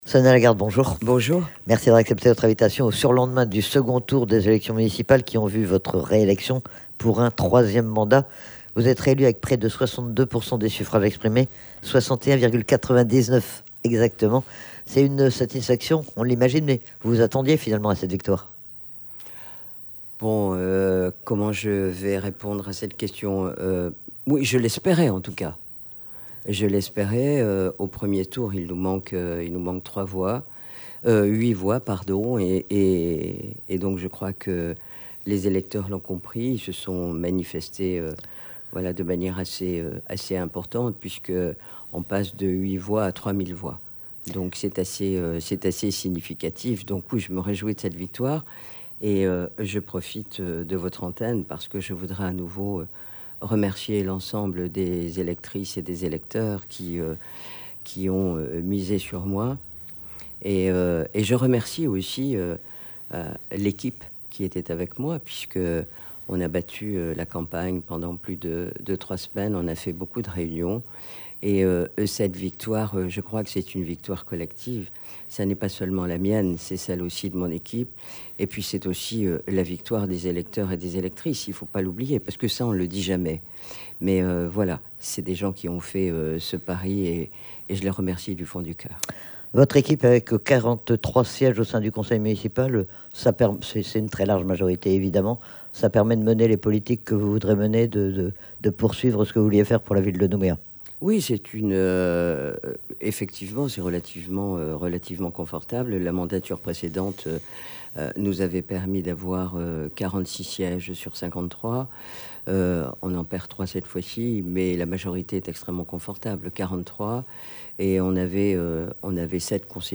L'INVITEE DU MATIN : Sonia Lagarde
Nous recevrons le maire réélu de Nouméa, Sonia Lagarde.